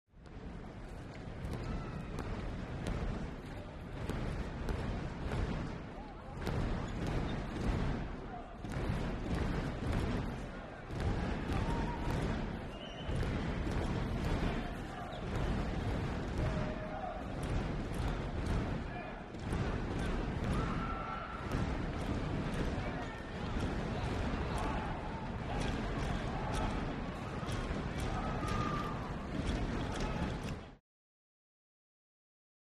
Stomping | Sneak On The Lot
Large Crowd Stomps Feet In Unison